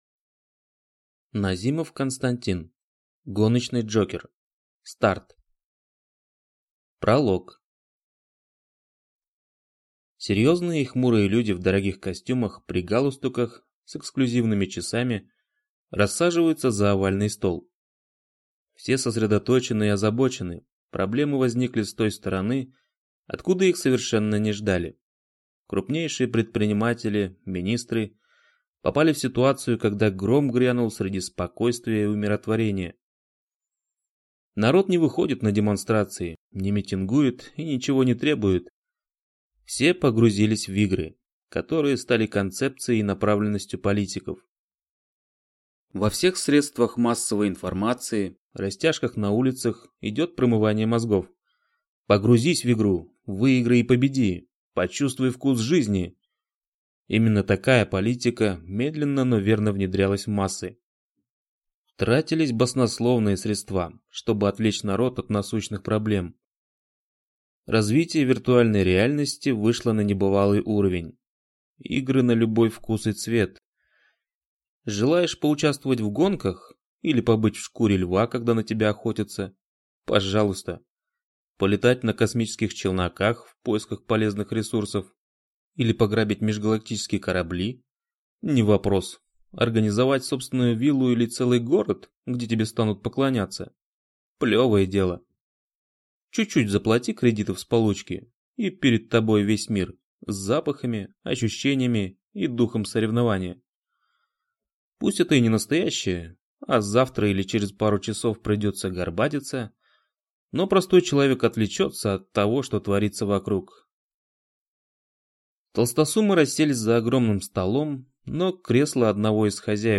Аудиокнига. У попаданца, с недавнего времени появилась возможность «умереть» не единожды, а целых пять раз…